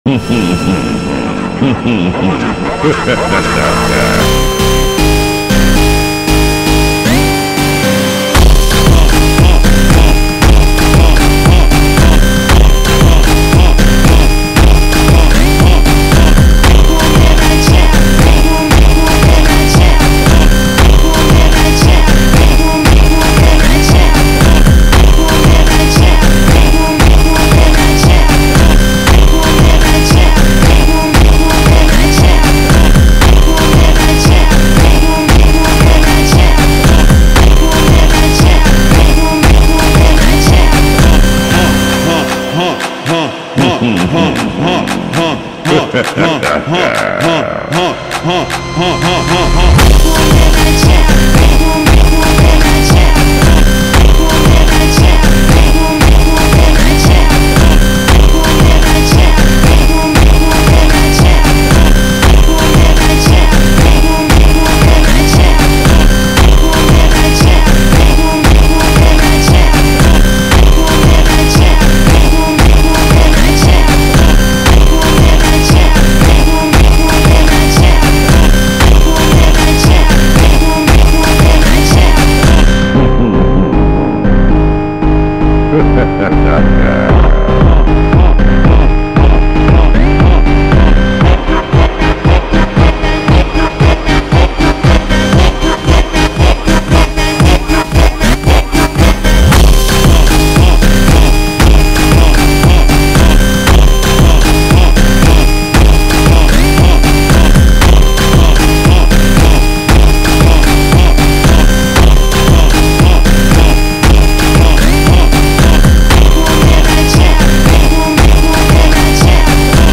دانلود فانک قدرتی
فانک